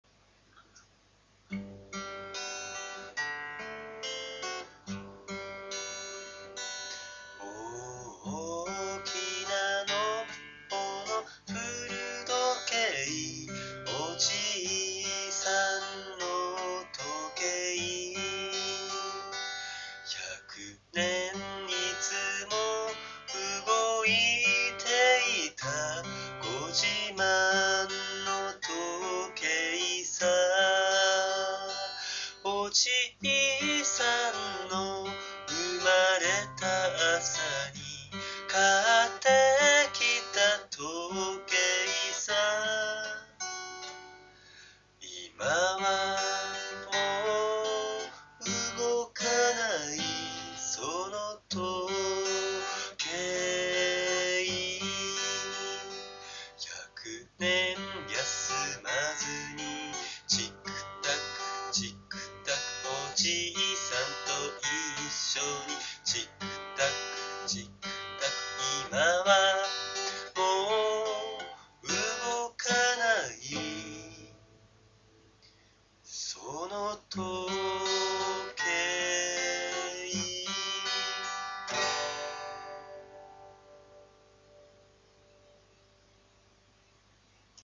アルペジオにチャレーンジ。 微妙な音源としては、初の生声収録。
もうちょっと練習しますorz 使用ギターは高音が綺麗に出るLL6にしてみました。
今回、マイクの感度を上げたので、多少ボリュームが上がっています。
使用ギター：YAMAHA LL6 使用弦：エリクサー ナノウェブ ライトゲージ 録音ソフト：YAMAHA SOL2 使用マイク：メーカー不明ヘッドマイク 大きな古時計.mp3